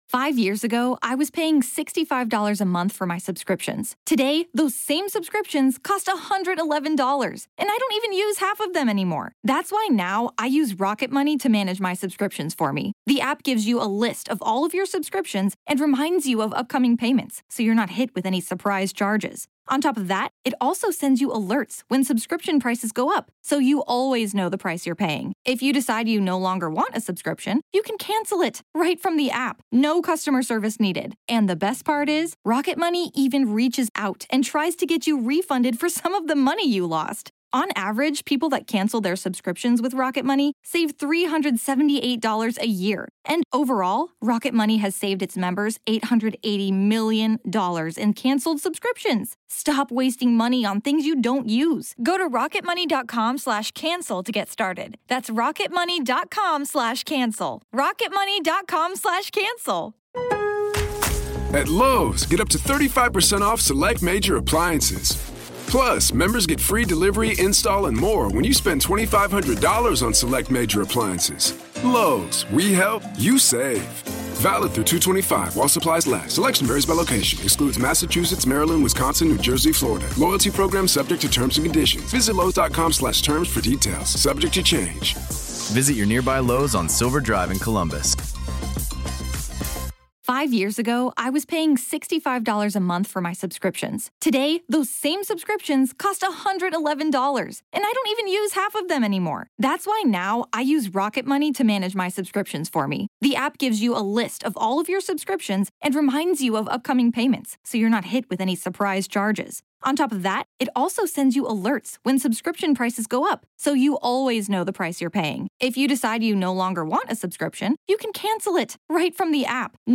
Karen Read Found Not Guilty - Live Coverage As It Happened